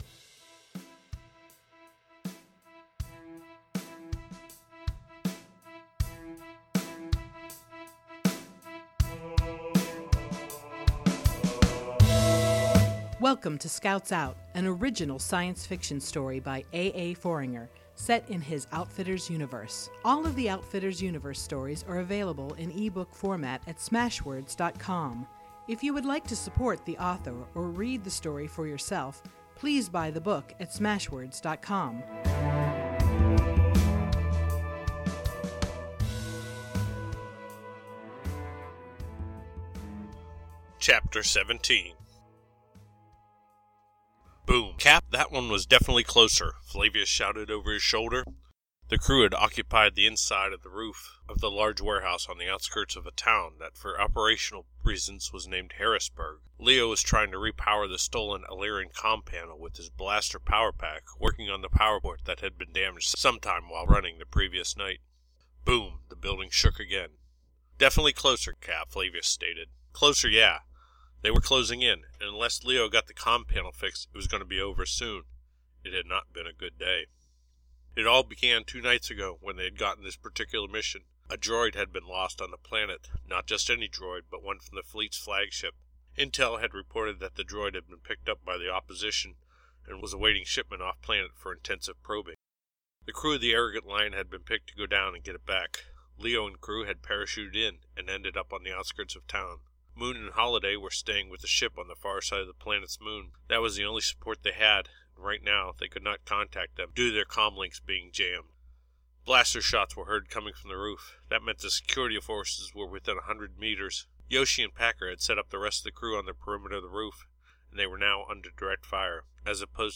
science fiction podcast